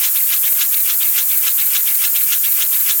Listen to the noise of a bearing in a CT scanner with a minor fault in installation.
Alten-SKF-CT-scan-noise.wav